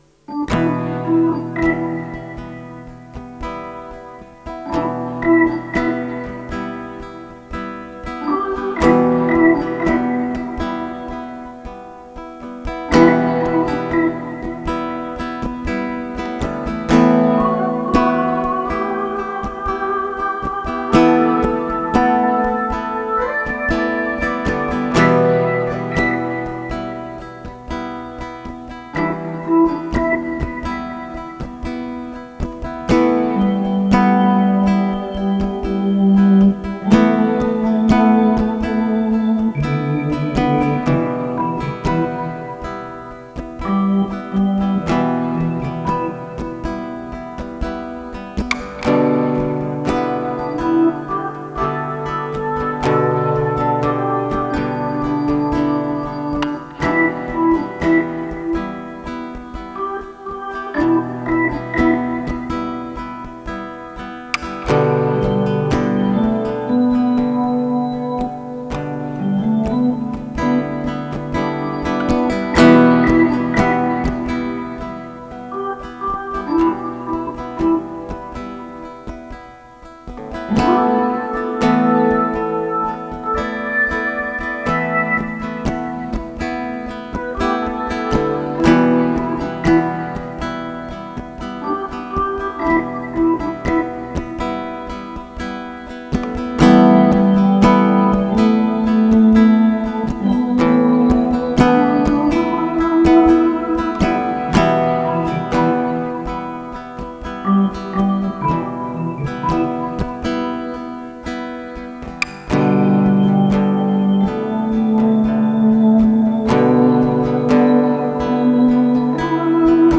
Western guitar with organ, peacefull .